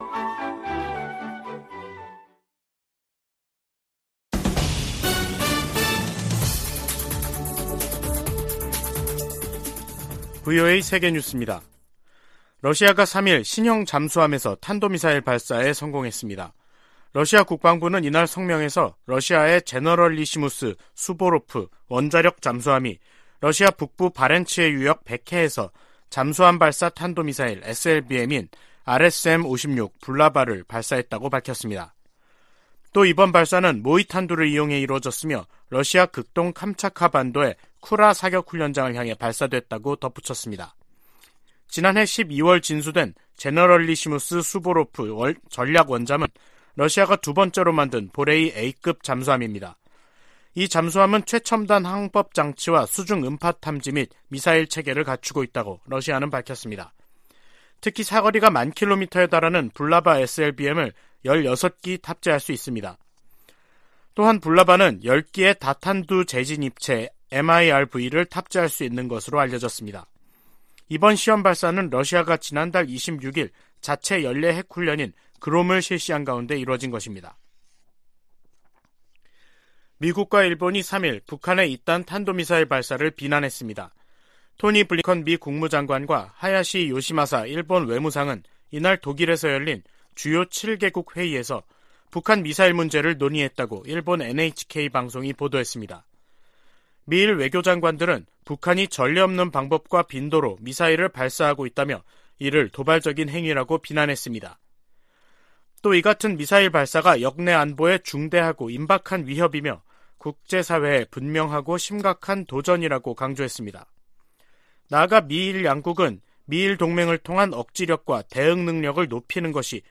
VOA 한국어 간판 뉴스 프로그램 '뉴스 투데이', 2022년 11월 4일 2부 방송입니다. 북한 김정은 정권이 오늘 대규모 군용기를 동원한 무력 시위를 하자 한국이 이에 대응해 스텔스 전투기 등 80여를 출격시키는 등 한반도에서 긴장이 계속되고 있습니다. 미국과 한국 국방장관이 미국 전략자산을 적시에 한반도 전개하는 방안을 강구하고 핵우산 훈련도 매년 실시하기로 합의했습니다.